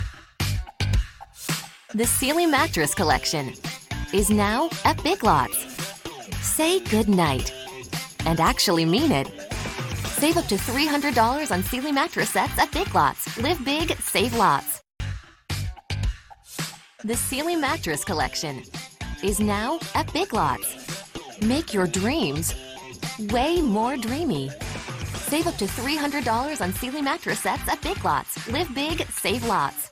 STUDIO & EQUIPMENT Whisper Room Isolation Booth Sennheiser 416 microphone Scarlett 2i2 Preamp
standard us | natural
COMMERCIAL 💸
warm/friendly